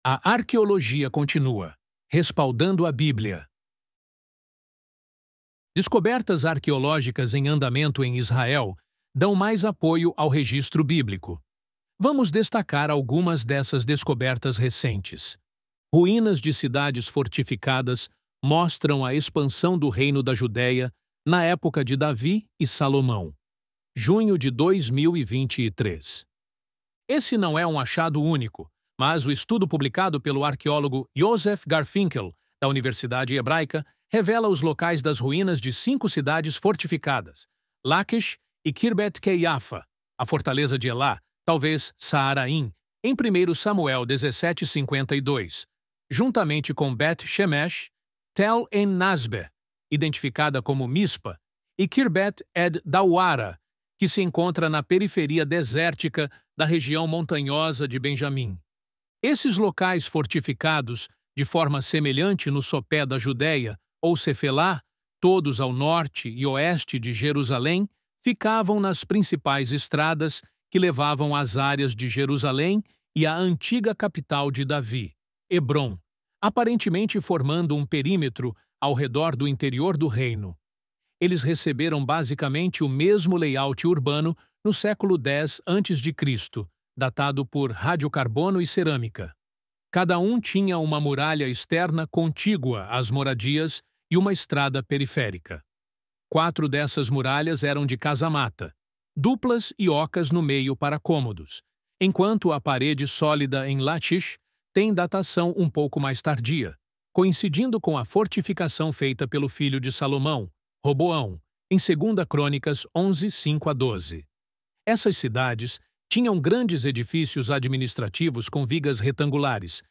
ElevenLabs_A_Arqueologia_Continua_Respaldando_a_Bíblia.mp3